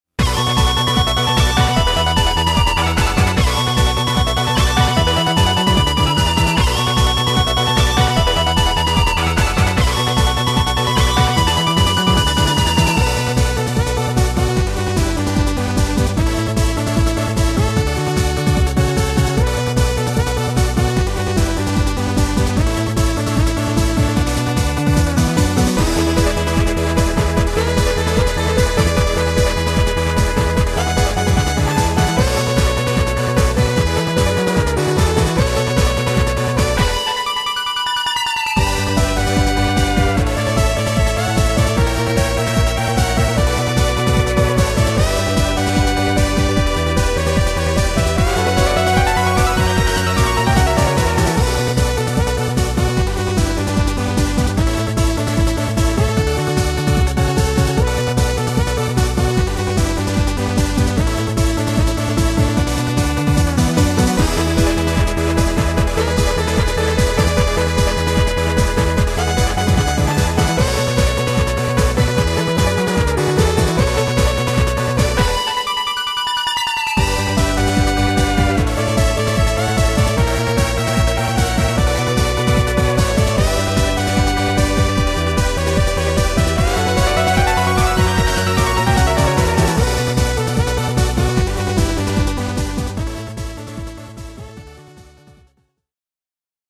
ゲーム中に使われているＢＧＭです（ＭＰ３フォーマット）。
※アーケード版の曲です。